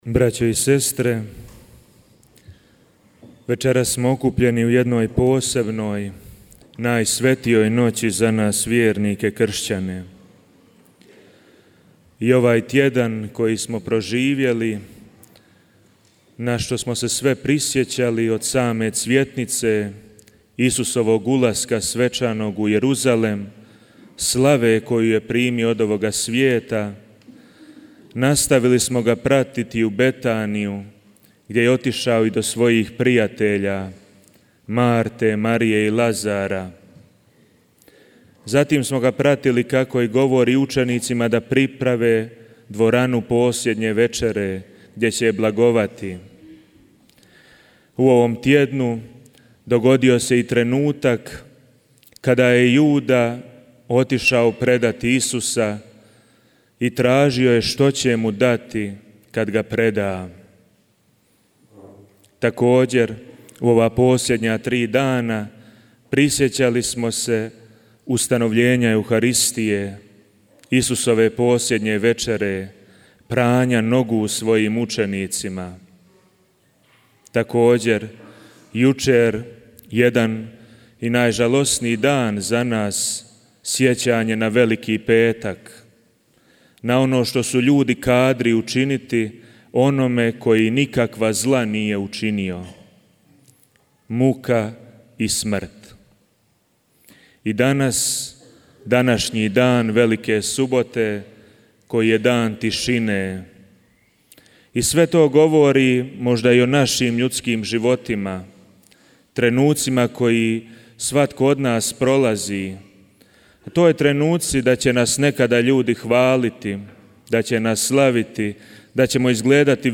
Vazmeno bdjenje, u kojem Crkva bdije iščekujući Kristovo uskrsnuće, održano je u subotu, 4. travnja 2026. i u Međugorju, a kao i svake večeri u crkvi sv. Jakova prethodila mu je molitva krunice.